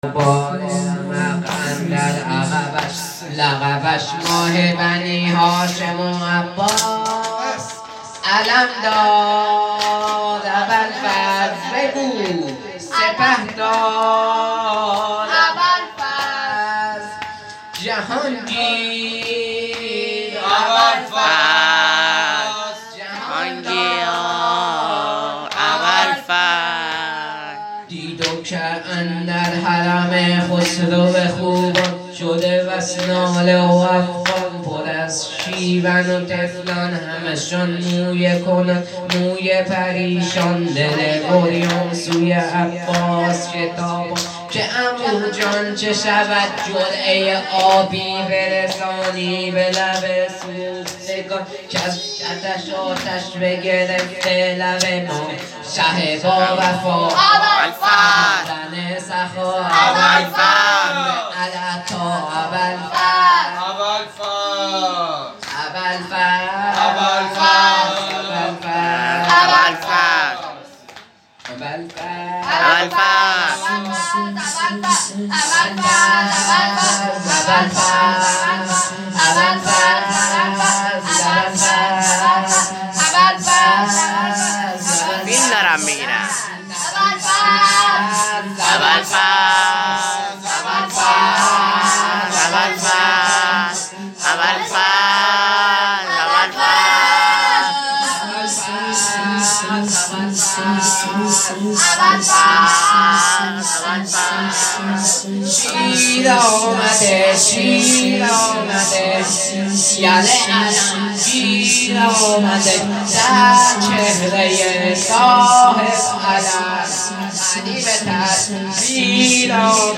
مداحی های محرم